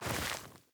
added stepping sounds
Ice_Mono_04.wav